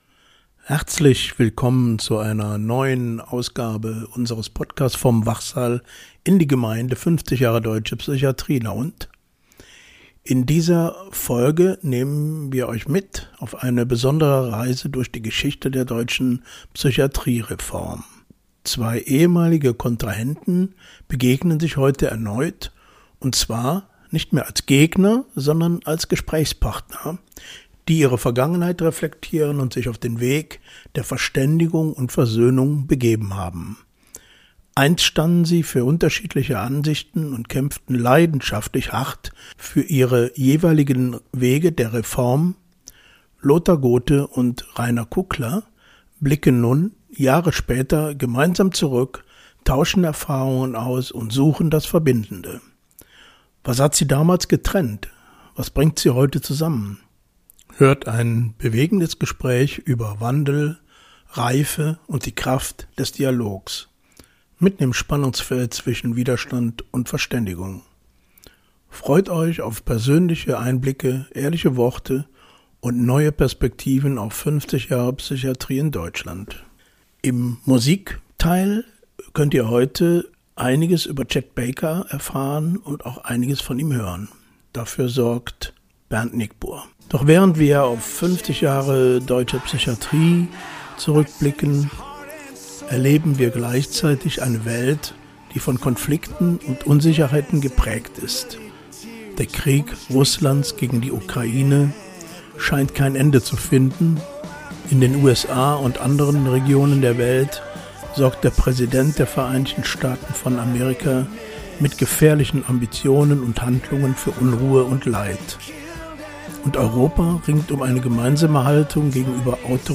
Was hat sie damals getrennt, was bringt sie heute zusammen? Hört ein bewegendes Gespräch über Wandel, Reife und die Kraft des Dialogs – mitten im Spannungsfeld zwischen Widerstand und Verständigung.